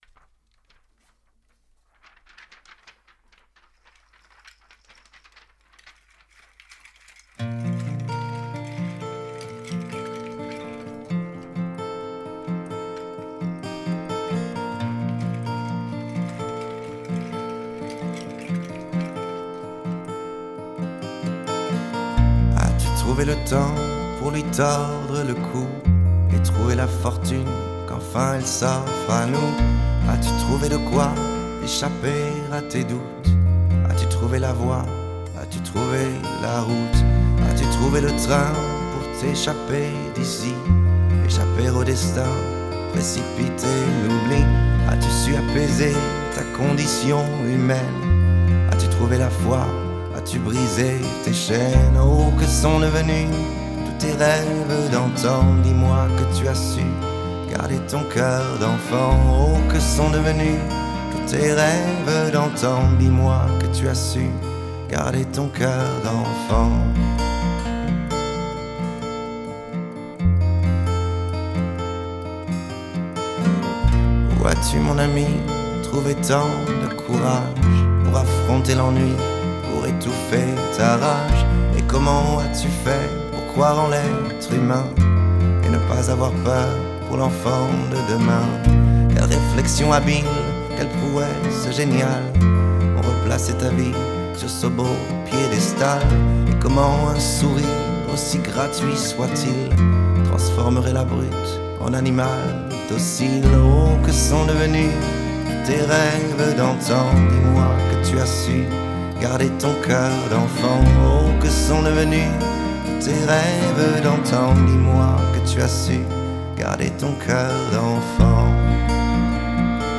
Chanteur
33 - 50 ans - Basse